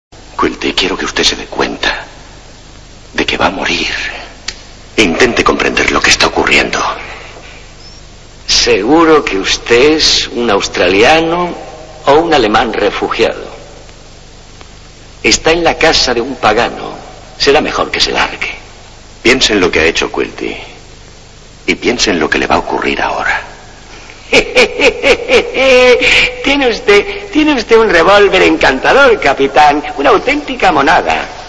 pulsando aquí, ha sido emitida por TV con sonido castellano.